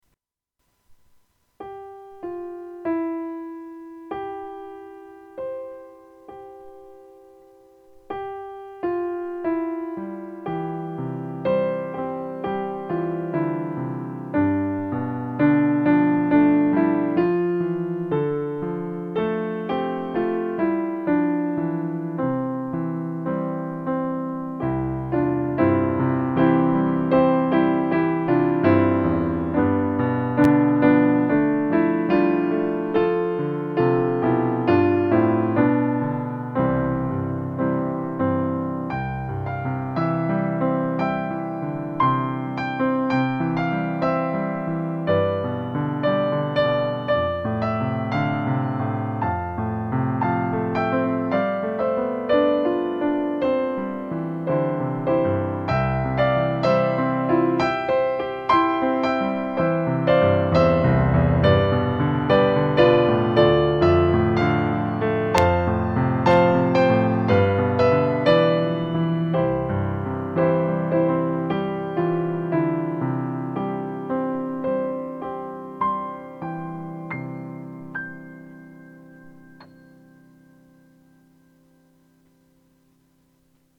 Pro tento týden jsem si pro vás připravil opět několik klavírních doprovodů písniček, které si můžete zazpívat třeba i se sourozenci nebo rodiči.